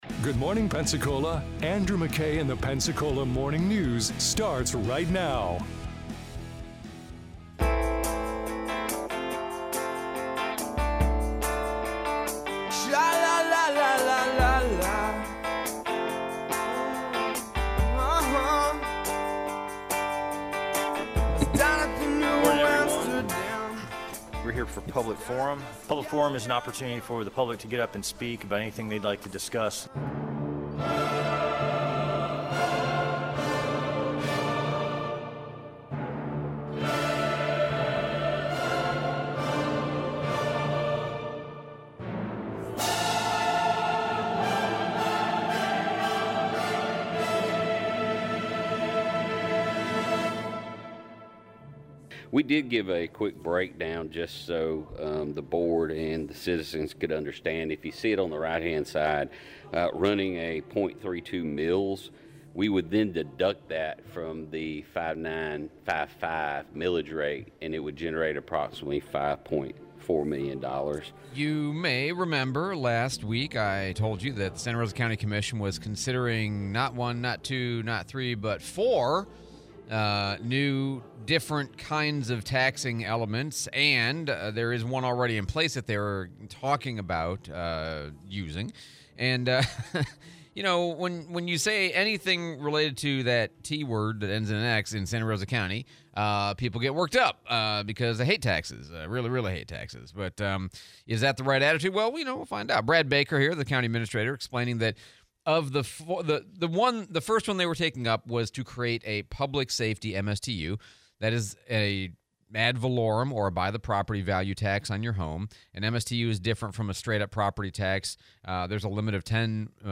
Santa Rosa County BOCC Meeting, replay of Alex Andrade interview